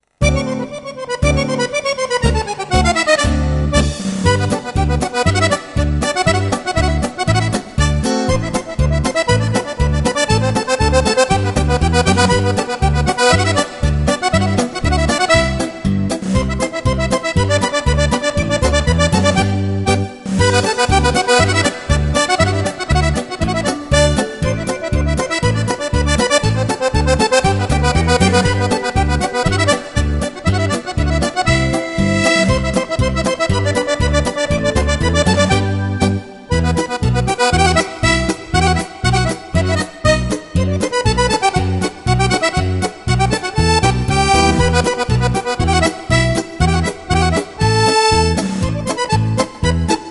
Polka
15 ballabili per Fisarmonica